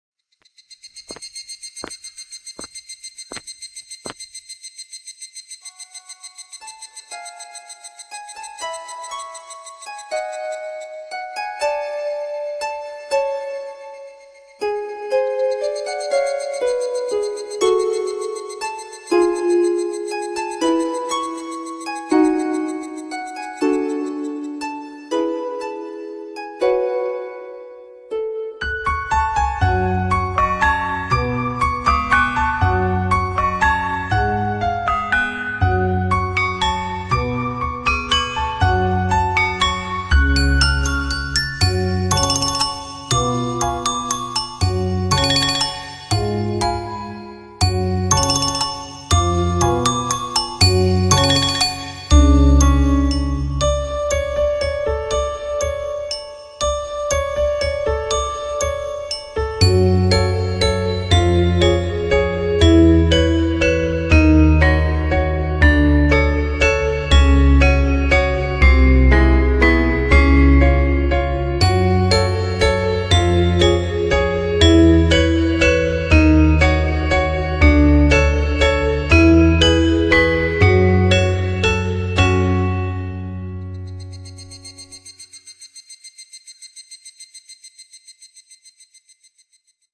【声劇】世界など所詮、